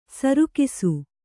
♪ sarukisu